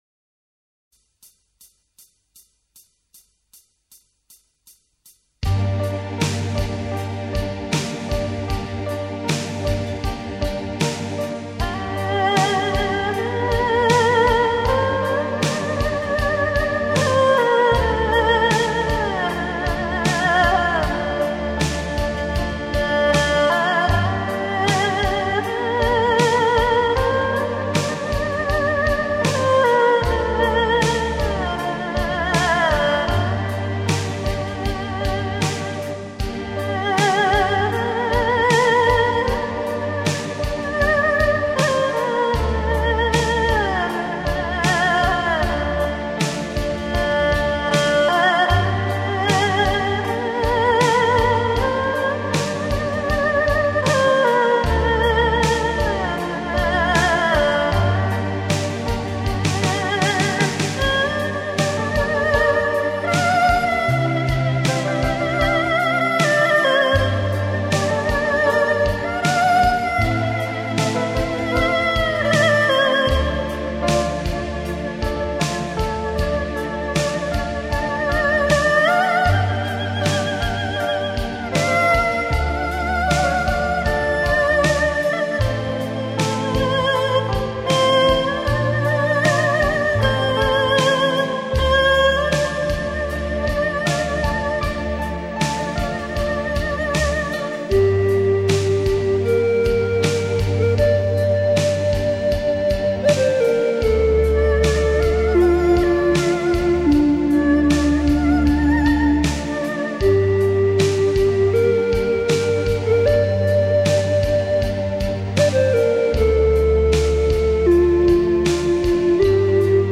[2005-9-4]宁静中隐含忧伤与无奈!!! 二胡演奏曲 激动社区，陪你一起慢慢变老！